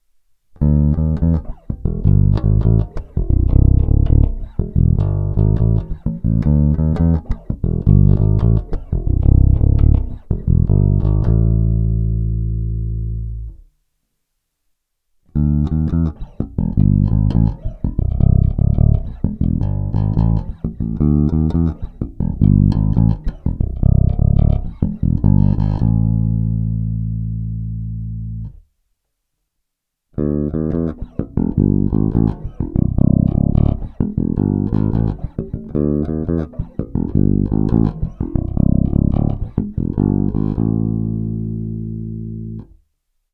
Co je naprosto parádní, je přednes struny H, brutální je to zvláště na kobylkový snímač.
Není-li uvedeno jinak, následující nahrávky jsou provedeny rovnou do zvukové karty a s korekcemi na středu a dále jen normalizovány, tedy ponechány bez postprocesingových úprav.
Ukázka s využitím struny H ve stejném pořadí jako výše